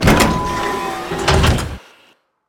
ElevatorOpen2.ogg